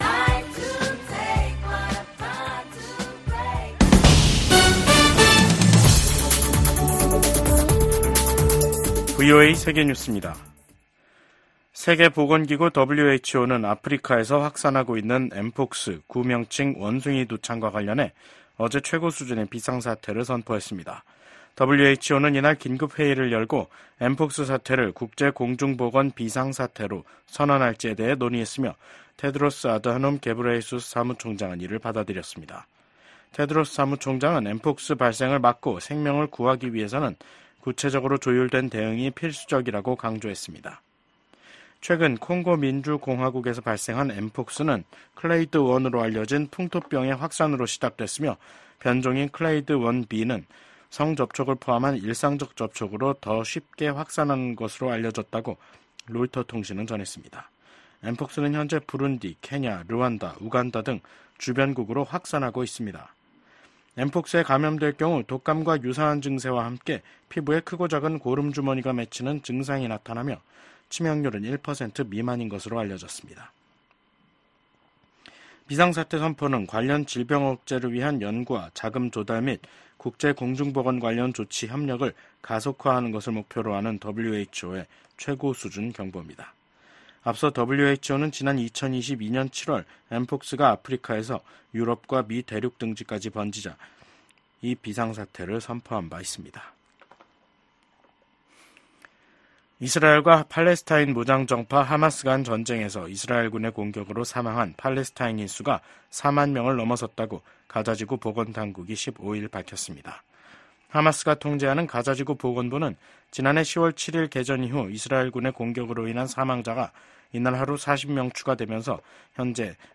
VOA 한국어 간판 뉴스 프로그램 '뉴스 투데이', 2024년 8월 15일 3부 방송입니다. 조 바이든 미국 대통령은 퇴임 의사를 밝힌 기시다 후미오 일본 총리가 미한일 협력 강화에 기여했다고 평가했습니다. 윤석열 한국 대통령은 광복절을 맞아 자유에 기반한 남북한 통일 구상과 전략을 ‘독트린’이라는 형식으로 발표했습니다. 북한이 2018년 넘긴 55개 유해 상자에서 지금까지 미군 93명의 신원을 확인했다고 미국 국방부 당국자가 말했습니다.